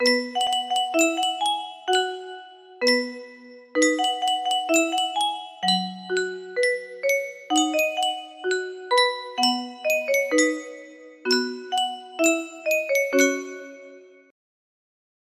Yunsheng Music Box - Unknown Tune Y931 music box melody
Full range 60